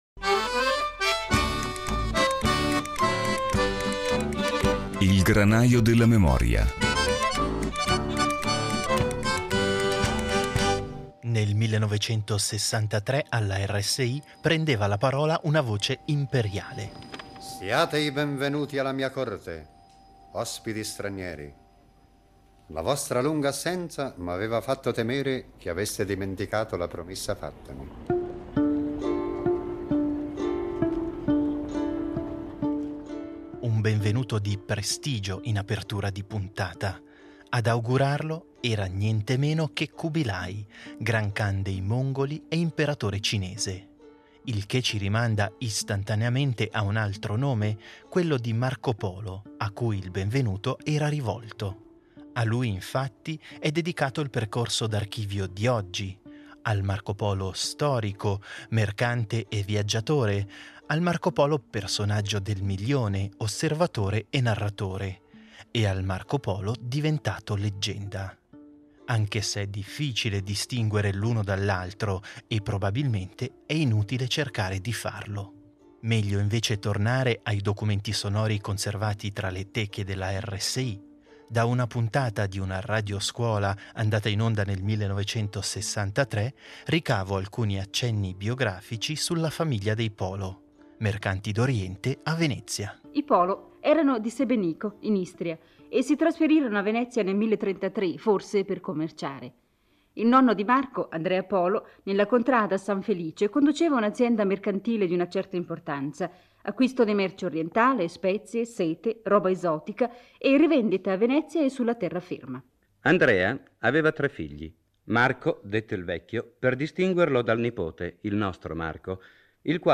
Sarà Marco Polo a guidarci questa settimana tra le Teche della RSI. O meglio, saremo noi a ricercare nell’archivio radiofonico tracce sonore che rimandano, più o meno direttamente, alla figura del mercante e viaggiatore veneziano, così come ai luoghi, ai popoli e a tutto quell'immaginario dell’Oriente che lo stesso Polo ha contribuito ad alimentare.